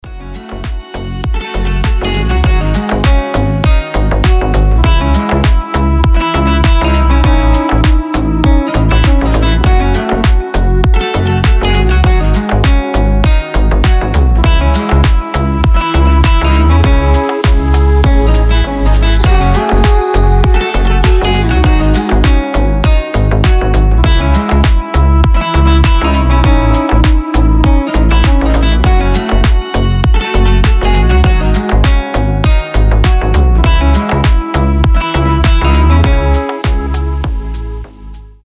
• Качество: 320, Stereo
гитара
deep house
красивая мелодия
релакс
Прекрасно-грустная мелодия для звонка